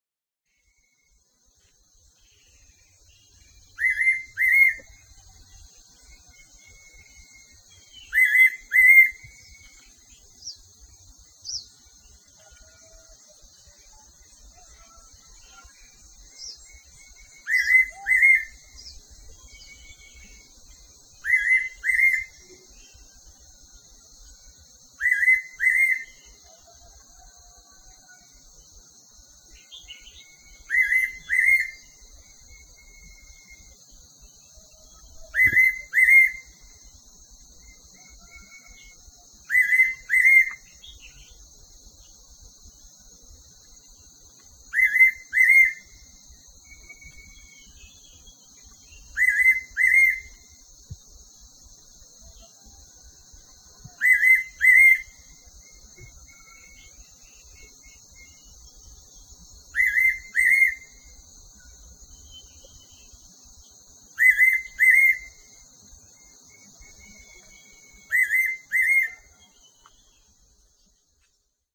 50-雲林湖本村-八色鳥 | 台灣聲景協會 Soundscape Association of Taiwan
50-雲林湖本村-八色鳥.mp3